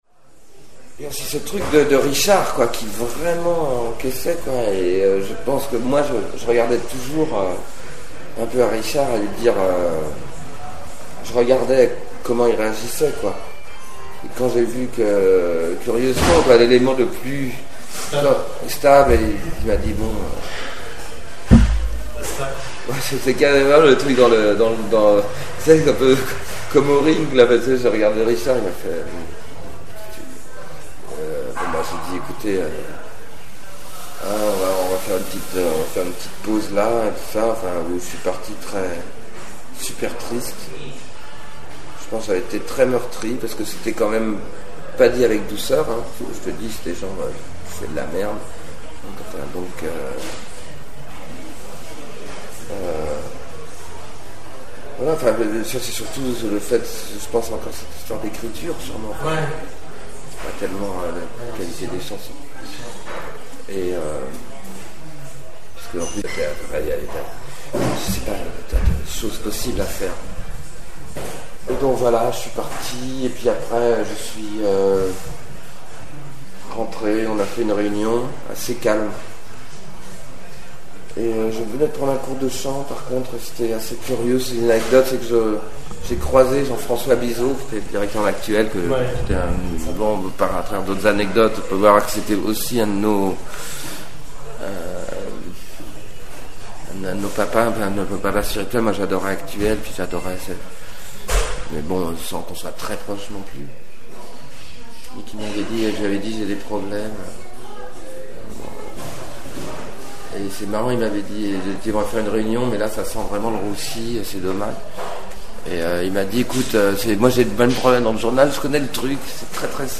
Voici quelques extraits d'interviews de Jean-Louis Aubert menées pour la biographie de Téléphone...